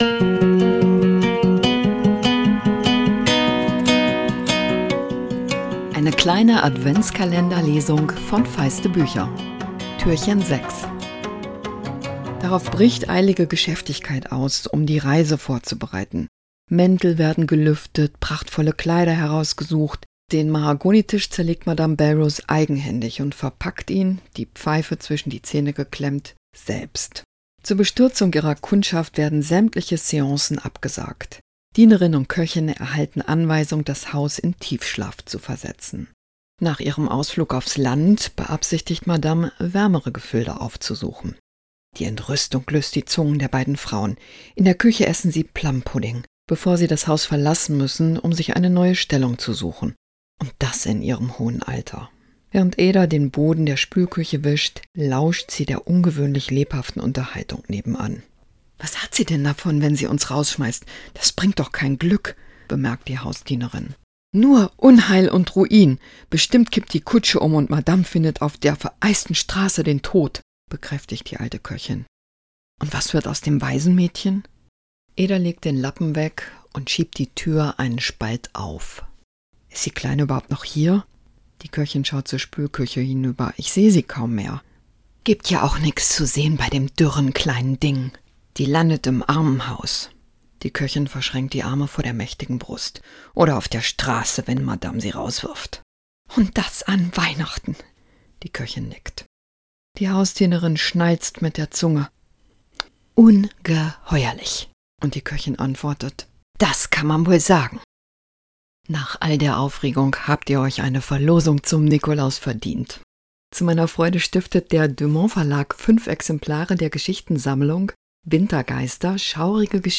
Adventskalender-Lesung 2024!